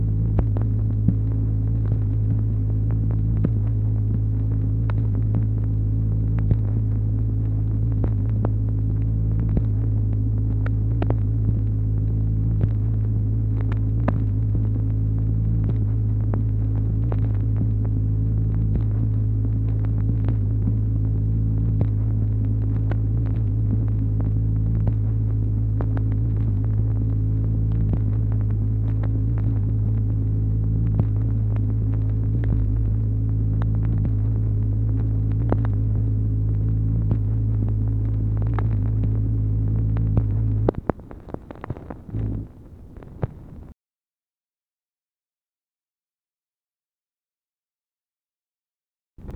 MACHINE NOISE, January 1, 1964
Secret White House Tapes | Lyndon B. Johnson Presidency